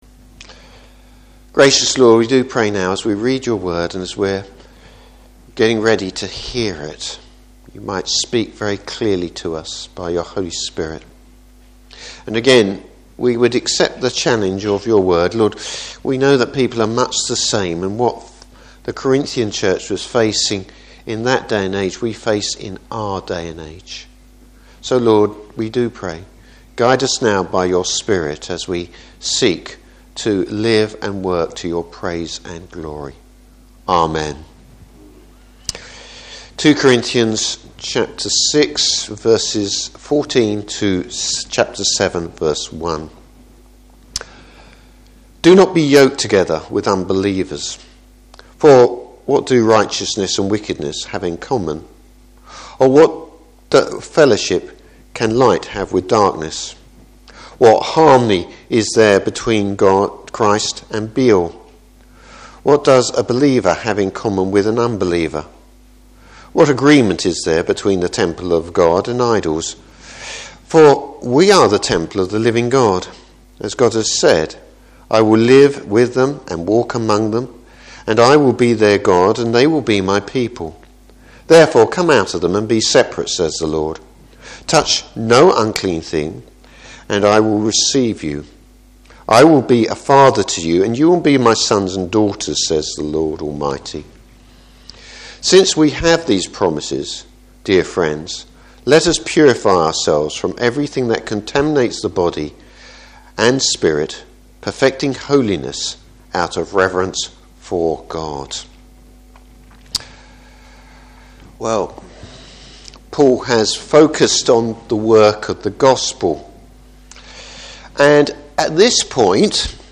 Service Type: Morning Service Paul’s warming concerning damaging relationships.